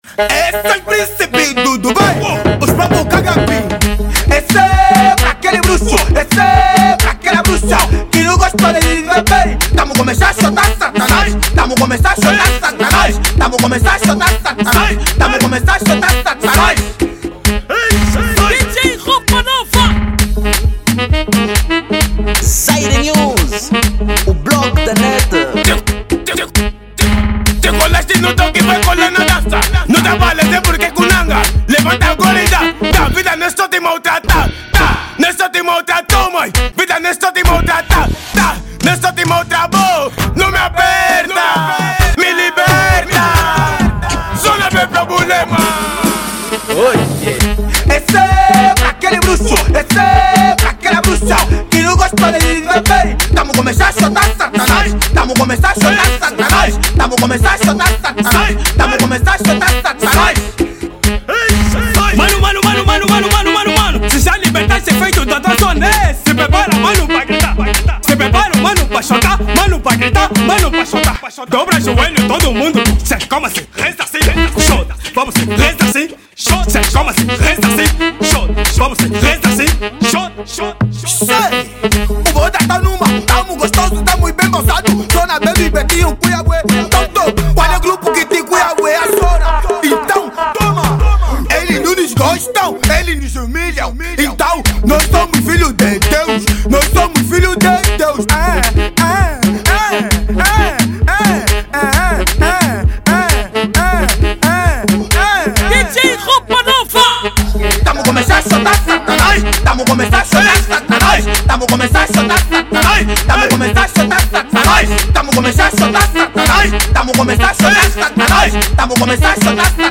Genero:Afro House